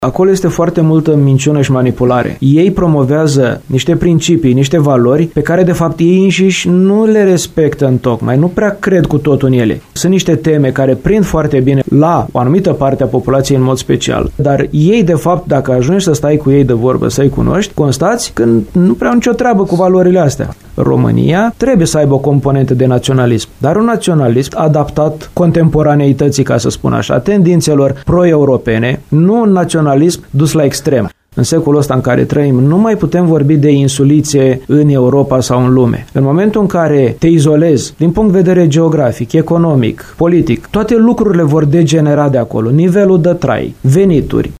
El a declarat postului nostru că retorica populistă în România se menține la cote ridicate, în special din cauza unor măsuri nepopulare  luate de alianța de la guvernare.